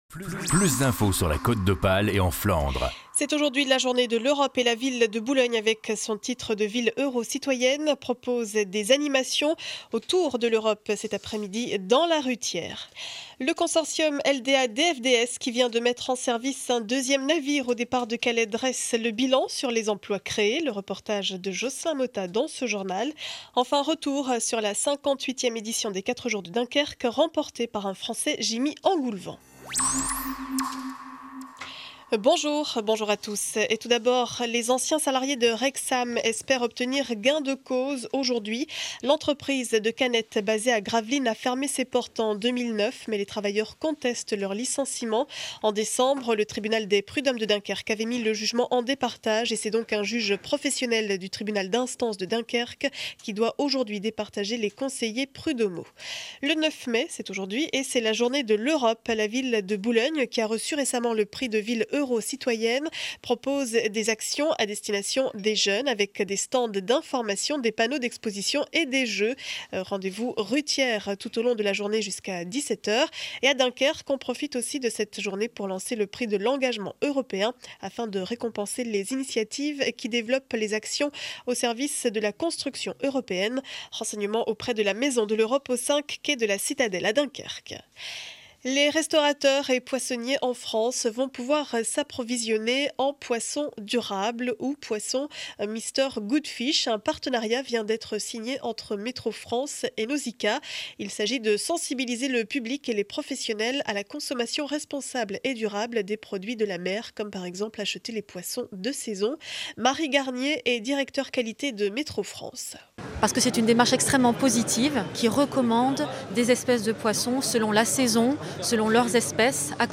Journal du mercredi 09 mai 2012, édition régionale.